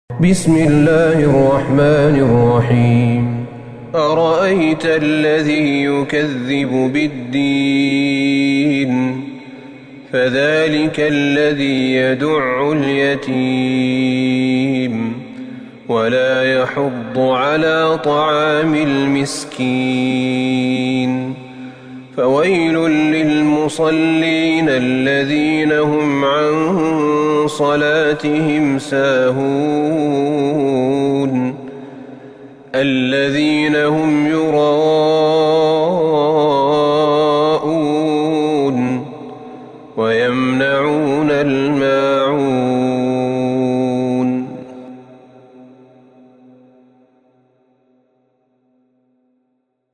سورة الماعون Surat Al-Maun > مصحف الشيخ أحمد بن طالب بن حميد من الحرم النبوي > المصحف - تلاوات الحرمين